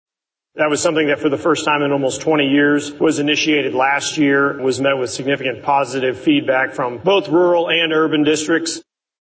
Senator Lincoln Hough (R-Springfield) chairs the Missouri Senate Appropriations Committee. He says House Bill 2 appropriates state funding for the Missouri Department of Elementary and Secondary Education, which includes fully funding school transportation